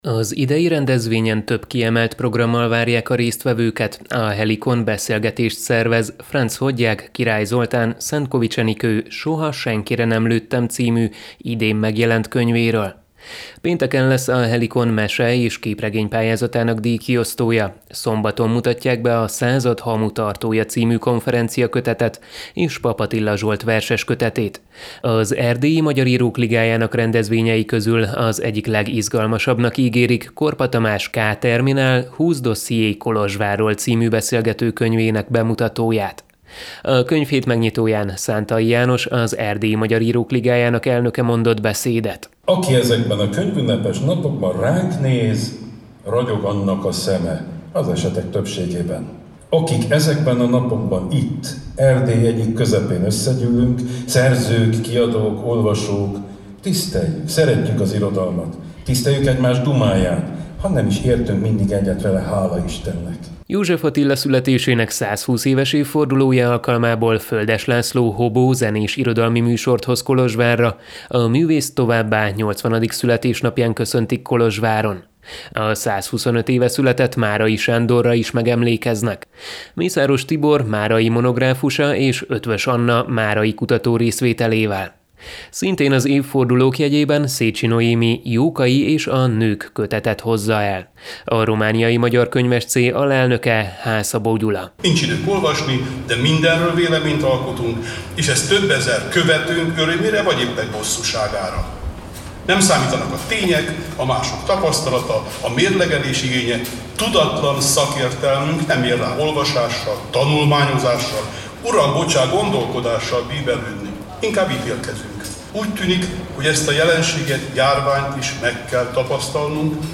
Megnyitották a 14. Kolozsvári Ünnepi Könyvhét programsorozatát a kolozsvári Bánffy-palota udvarán.